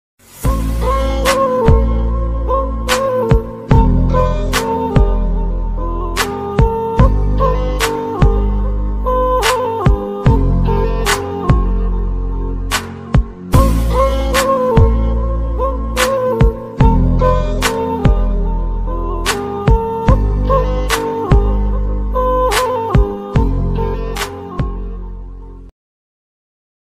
musical ringtones